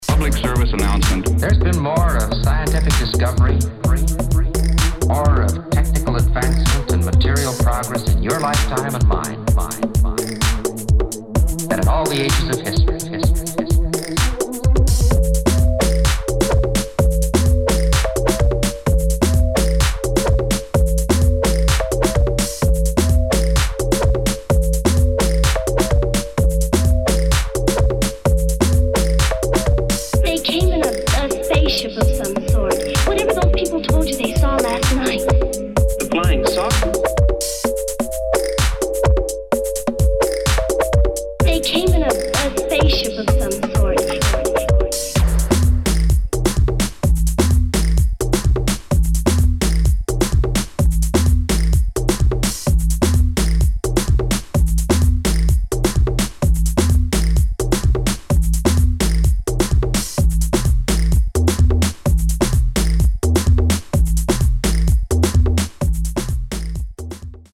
[ UK GARAGE | BASS ]